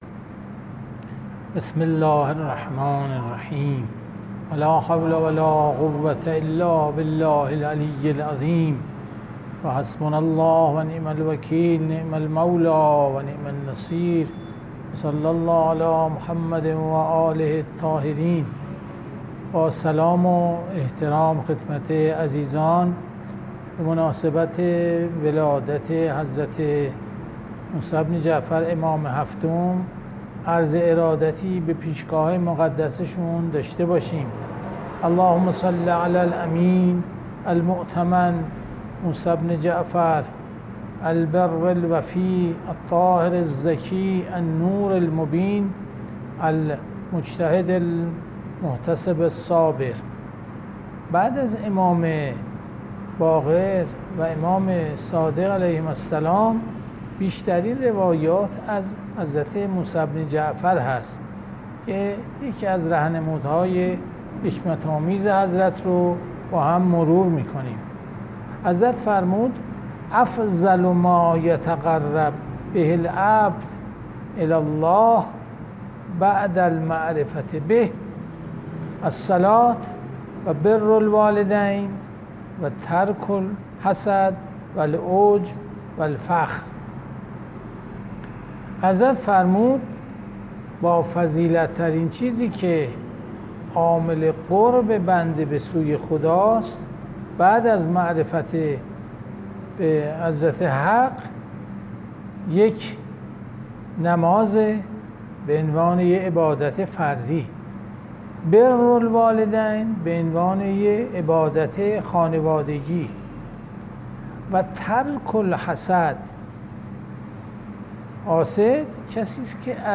جلسه مجازی هفتگی قرآنی، سوره فجر، 10 مرداد 1400
تفسیر قرآن